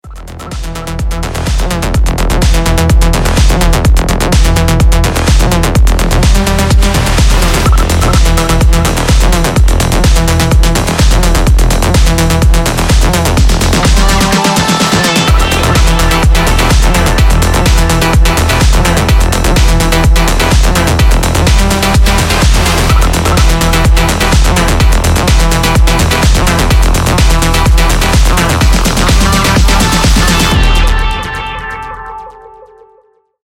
Клубные Рингтоны
Танцевальные Рингтоны » # Рингтоны Электроника